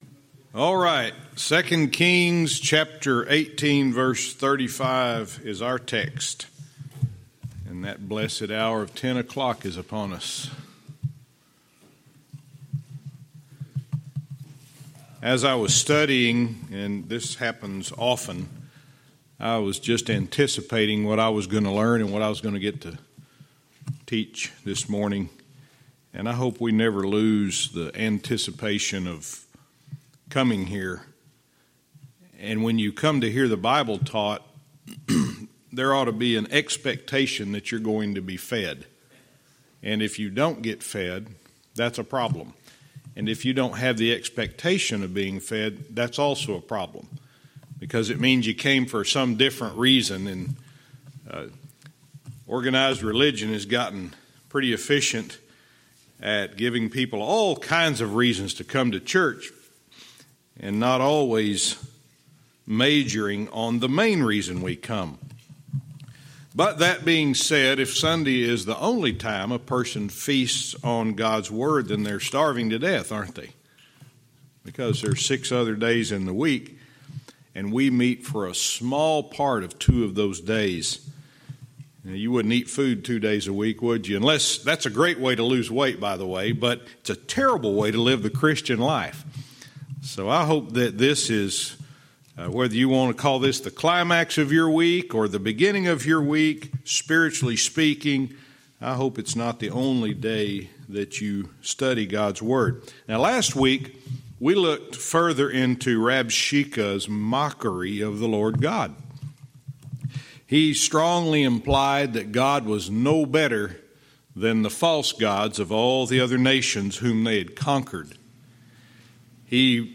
Verse by verse teaching - 2 Kings 18:35-19:1